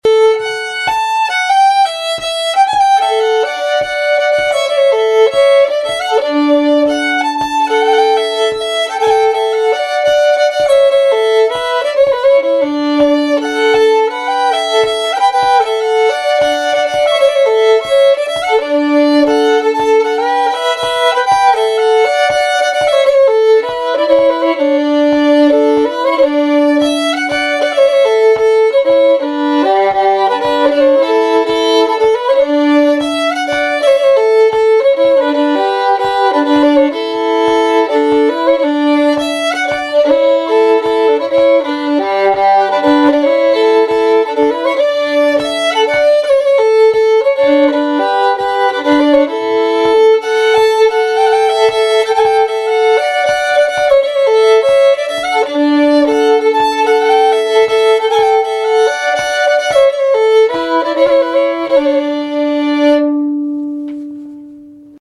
(slow, fast)